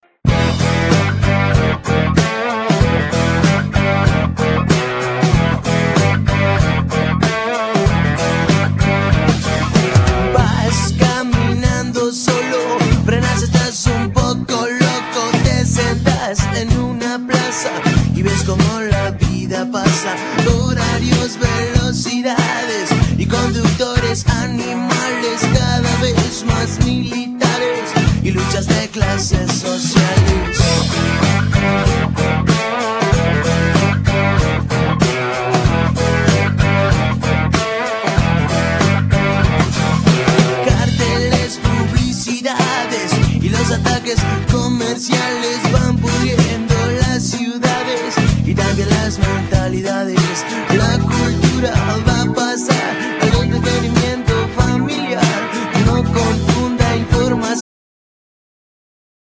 Genere : Pop Latin
Guitarra, Voz, Coros, Samplers y Secuencias